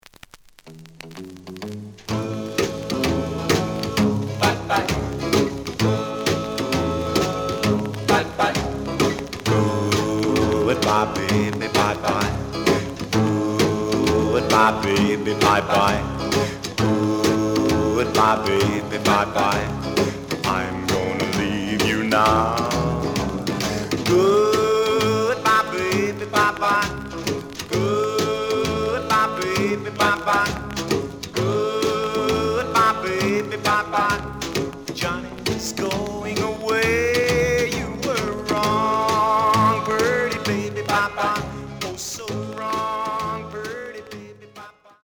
The listen sample is recorded from the actual item.
●Genre: Rhythm And Blues / Rock 'n' Roll
Slight edge warp.